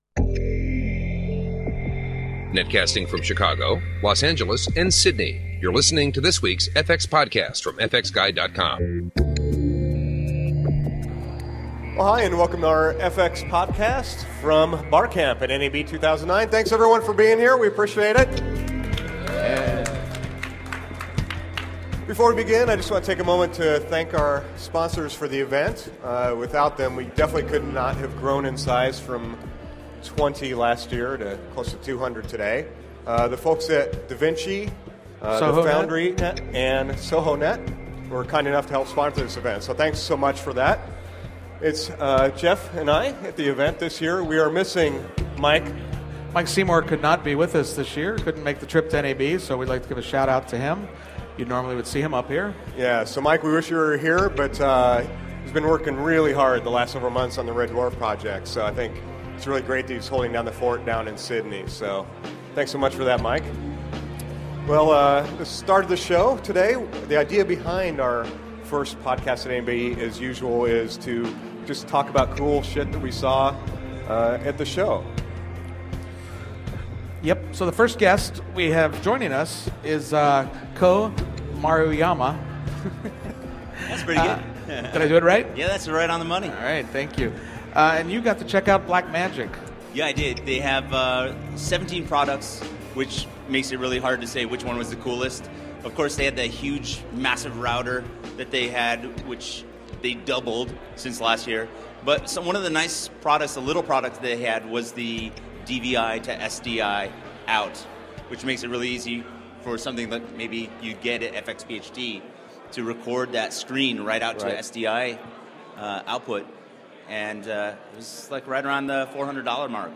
We talk with friends as well as fxphd post grads and professors about what they saw on the first day of the show. Recorded live at a party sponsored by The Foundry, Sohonet and da vinci.